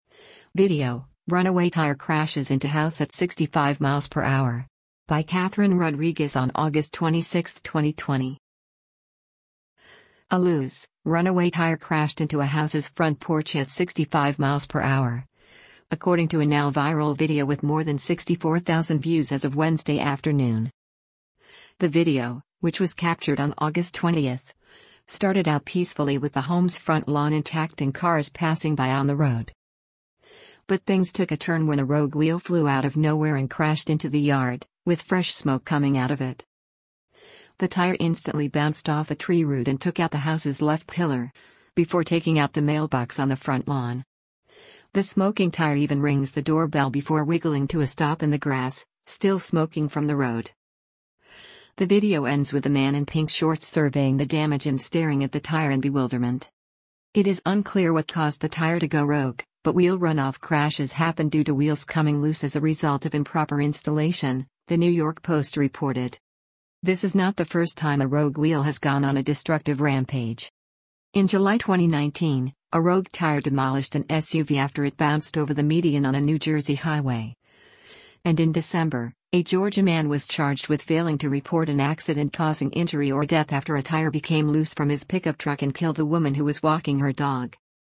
The video, which was captured on August 20, started out peacefully with the home’s front lawn intact and cars passing by on the road.
The smoking tire even rings the doorbell before wiggling to a stop in the grass, still smoking from the road.